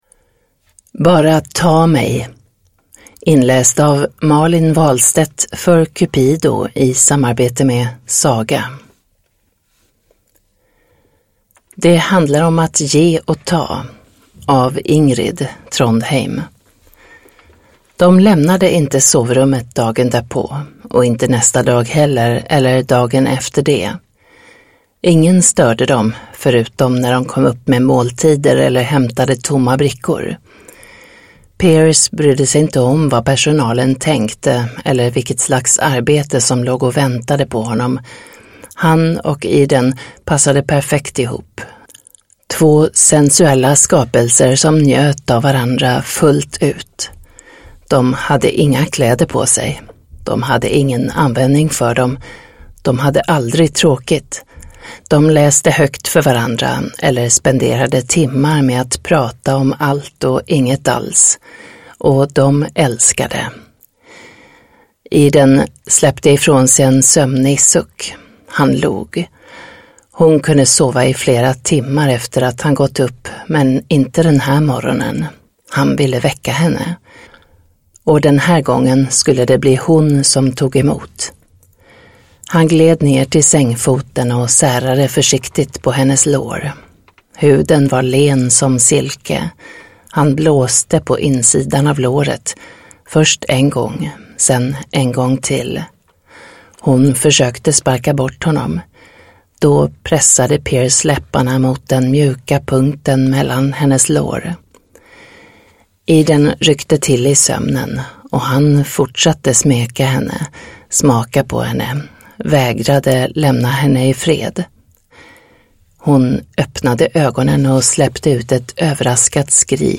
Bara ta mig! (ljudbok) av Cupido